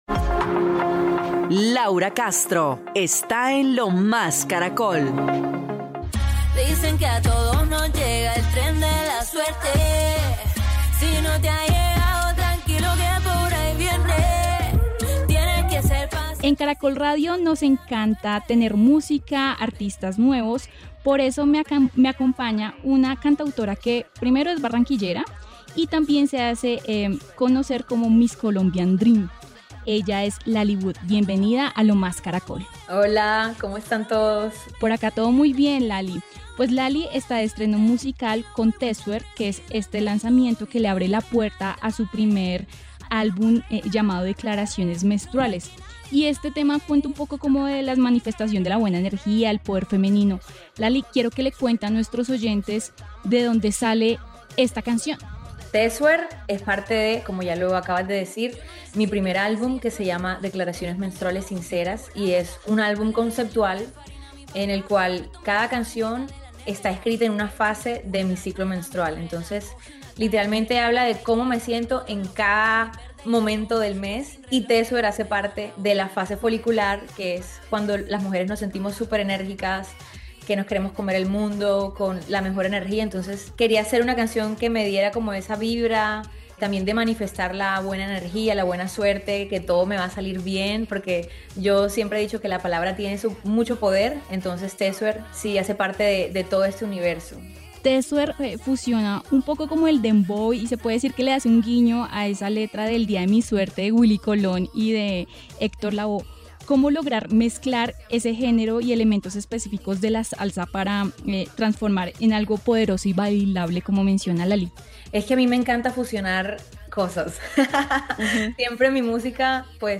En los micrófonos de Lo Más Caracol